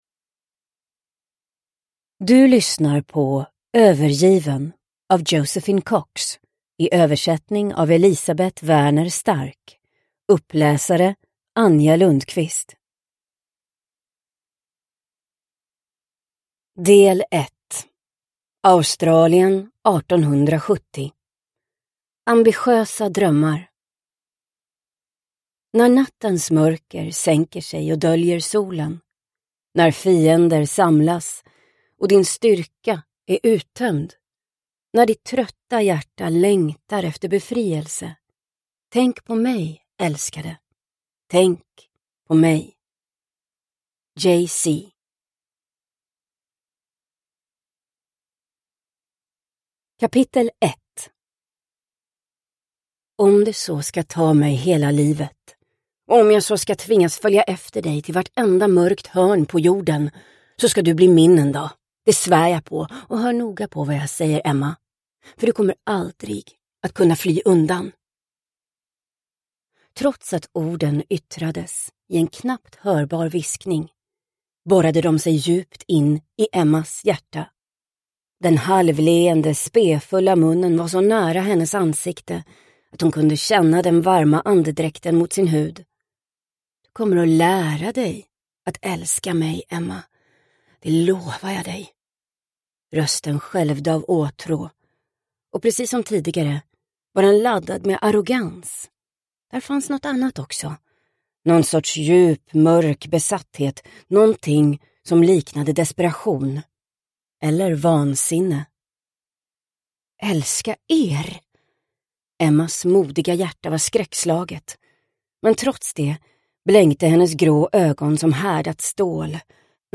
Övergiven – Ljudbok – Laddas ner